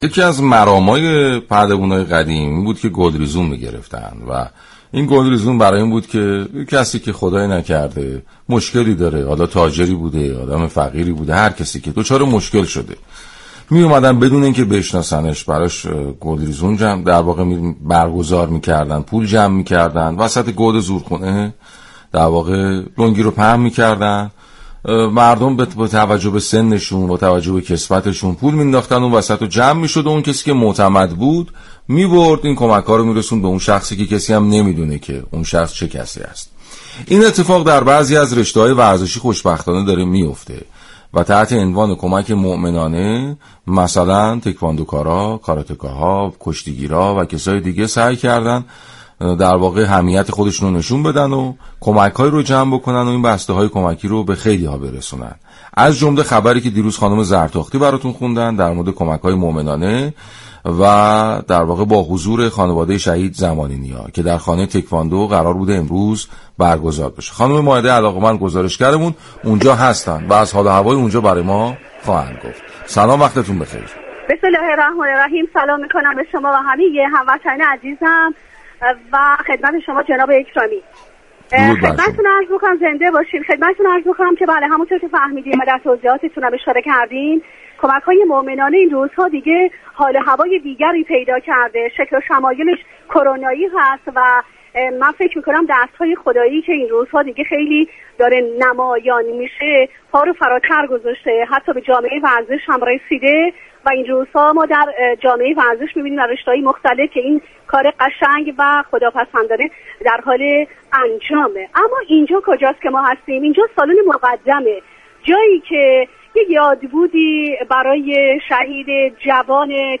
برنامه "نیمكت" با محوریت سرگرمی و خانواده، هر روز به جز جمعه ها ساعت 11:00 به مدت 50 دقیقه از شبكه رادیویی ورزش پخش می شود.